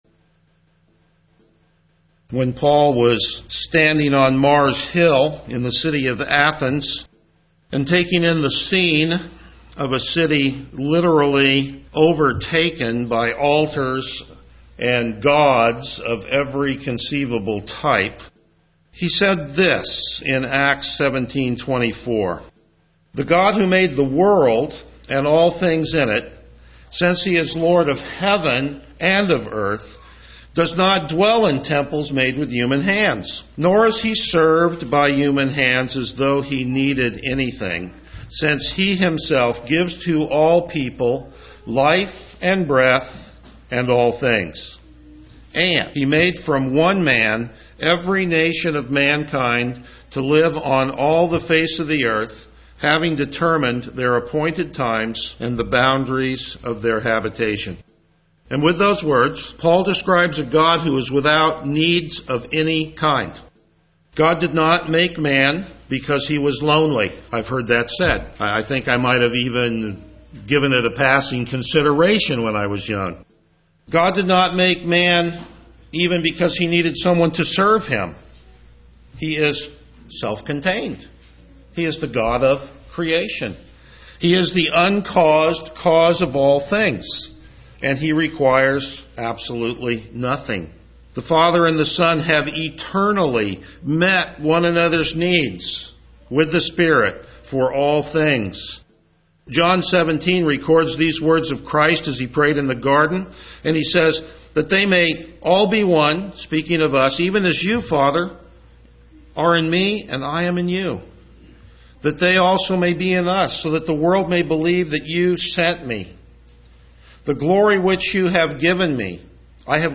Passage: Revelation 4:6-11 Service Type: Morning Worship